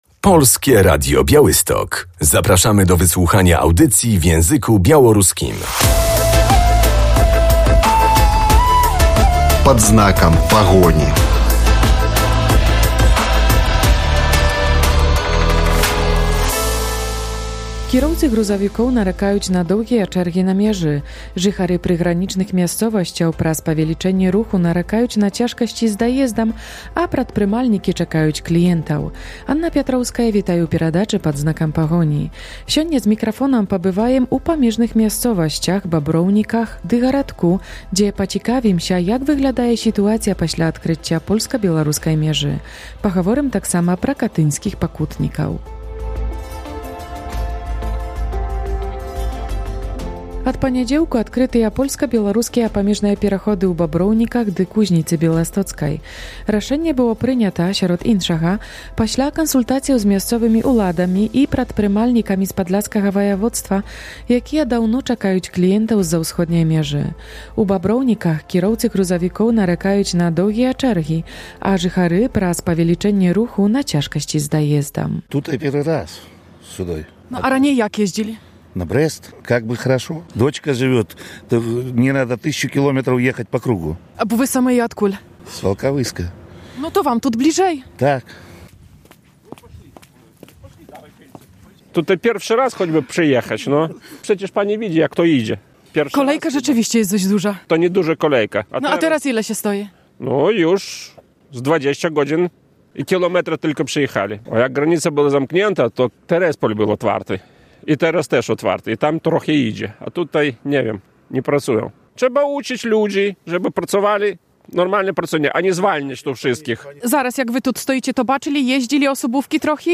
Z mikrofonem odwiedzamy polsko-białoruskie przejście graniczne w Bobrownikach 20.11.2025